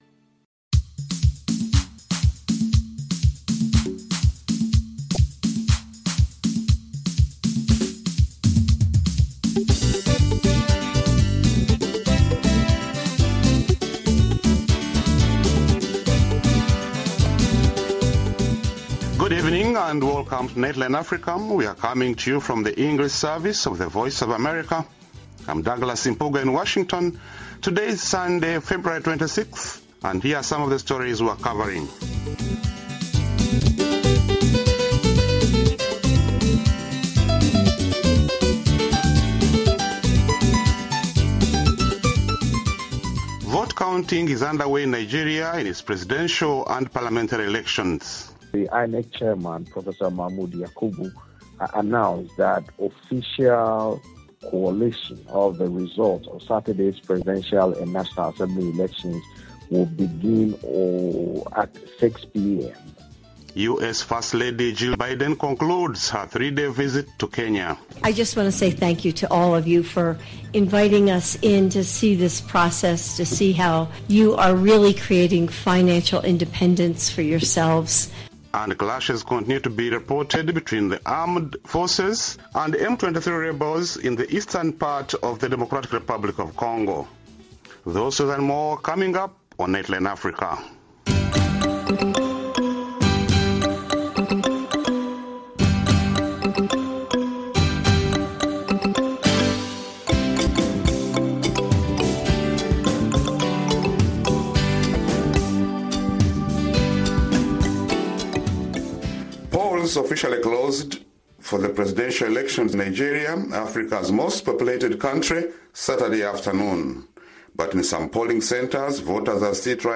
Join our host and correspondents from Washington and across Africa as they bring you in-depth interviews, news reports, analysis and features on this 60-minute news magazine show.